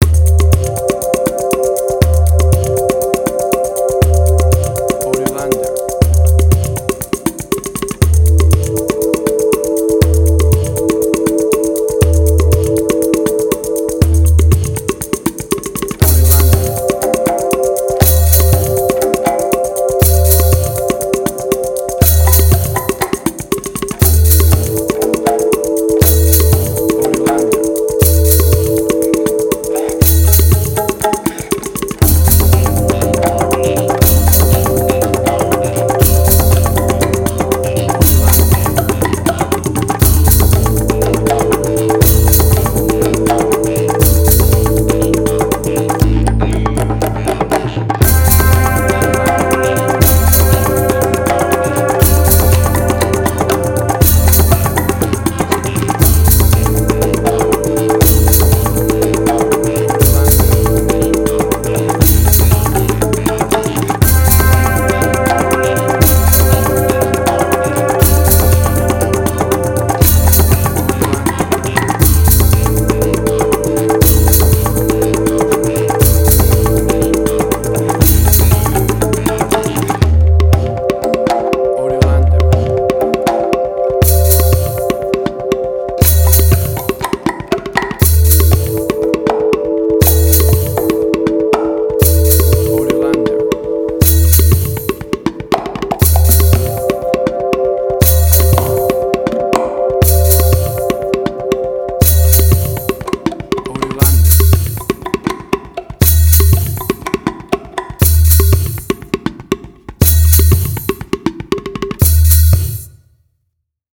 Middle Eastern Fusion.
Tempo (BPM): 120